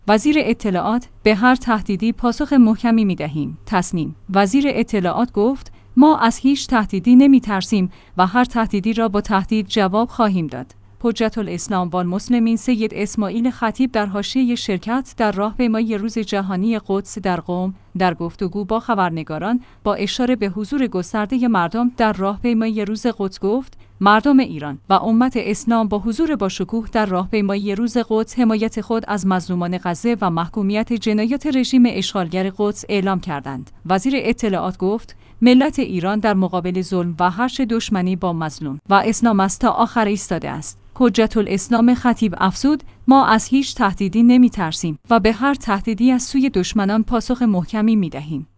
در حاشیه شرکت در راهپیمایی روز جهانی قدس در قم، در گفتگو با خبرنگاران